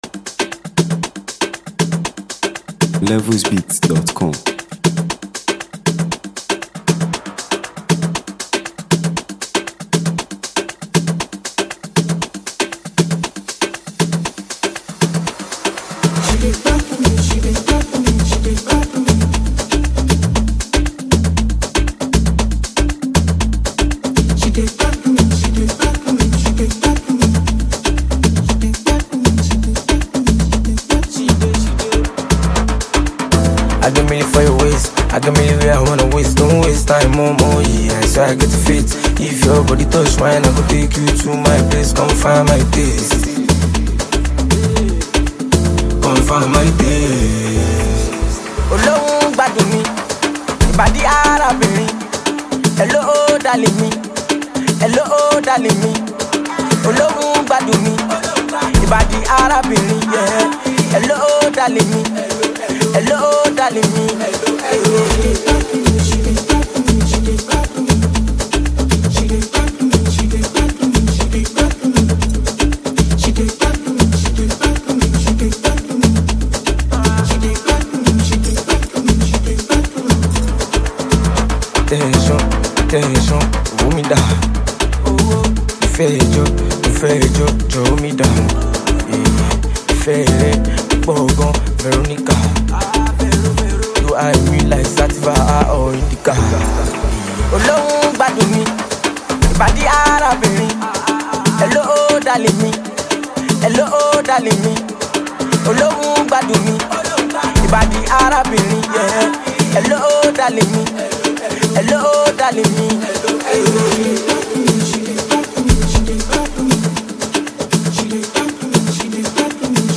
Nigeria Music 2025 2:14
infectious rhythms
For lovers of fresh Afrobeat vibes